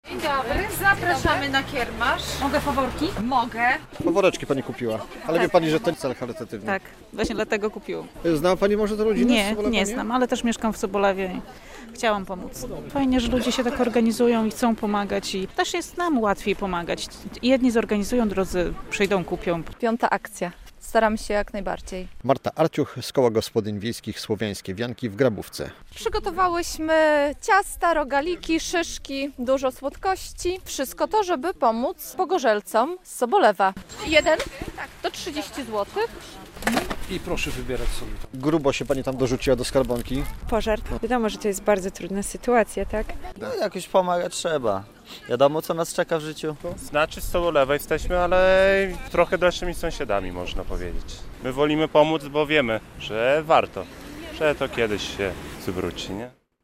Koło gospodyń wiejskich "Słowiańskie Wianki" wraz z parafią Świętego Krzyża w Grabówce zorganizowało w niedzielę (23.02) specjalny kiermasz połączony ze zbiórką datków dla rodziny z Sobolewa, która dwa tygodnie temu straciła dach nad głową.